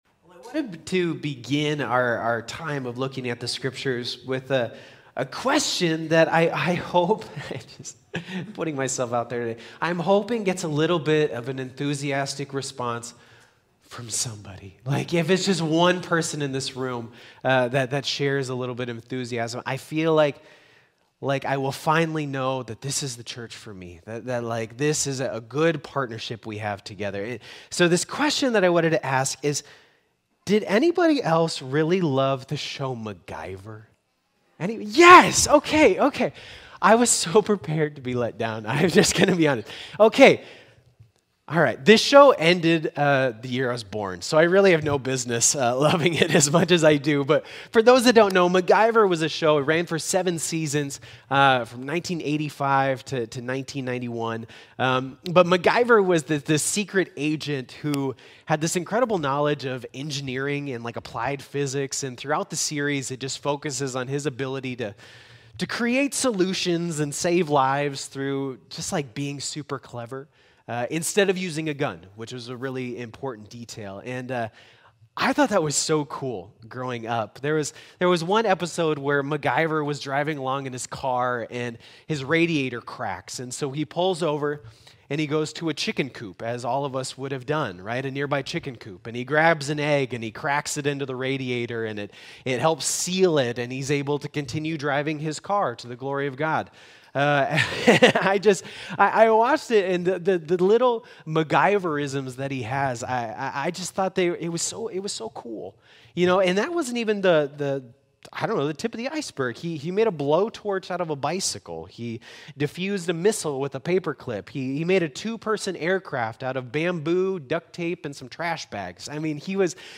Sermons | Harrisburg United Methodist Church
Thank you for joining us for online worship.